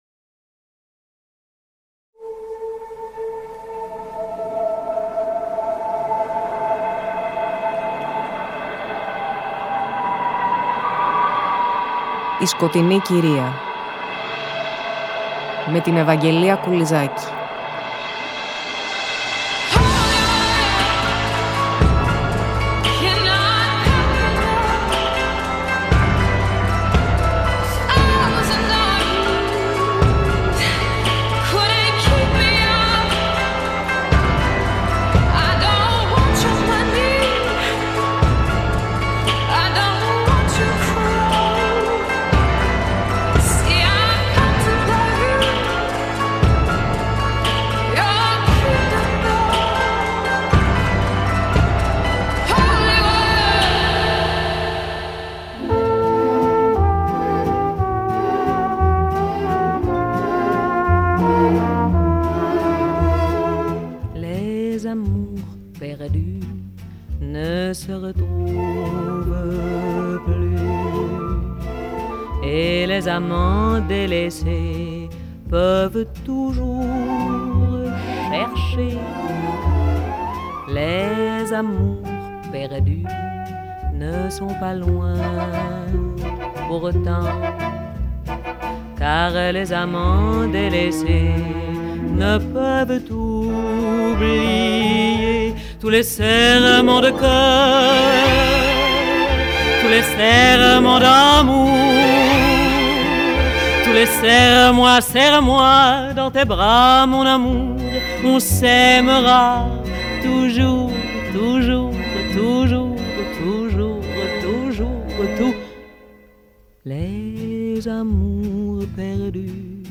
Στις εκπομπές του αφιερώματος ακολουθούμε τα βήματα μιας χαρισματικής γυναίκας που έγραψε διπλή ιστορία – τόσο ως μεγάλη καλλιτέχνιδα με εξ ολοκλήρου δικό της άστρο, όσο και ως ”παράνομη” ερωμένη του νομπελίστα συγγραφέα, σ’ ένα τρικυμιώδες ειδύλλιο φτιαγμένο από τα μυθιστορηματικά υλικά με τα οποία μας αιφνιδιάζει καμιά φορά η ζωή. ‘Bonus” στην εκπομπή η φωνή της ίδιας της Καζαρές – την ακούμε να απαγγέλει Λόρκα, καθώς και σε αποσπάσματα από την κινηματογραφική συνεργασία της με τον Κοκτώ.